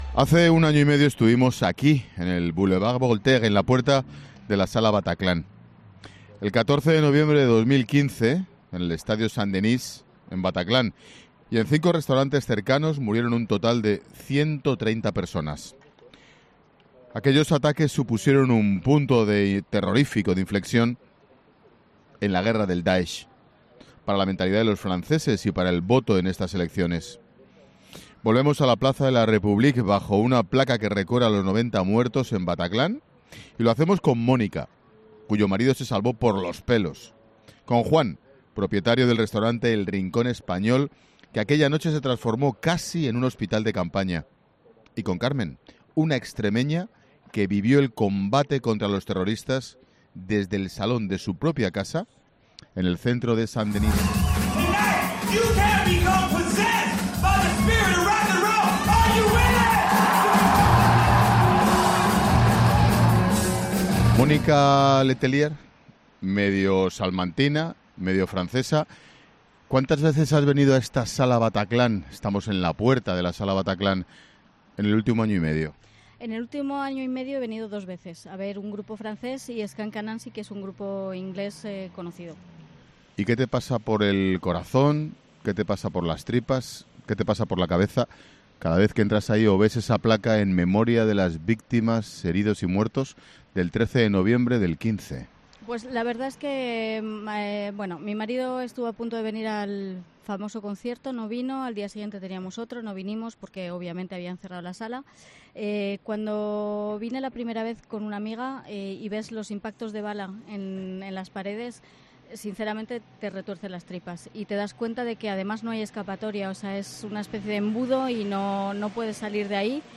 'La Tarde' de Expósito se emite este lunes desde París con motivo de las elecciones francesas.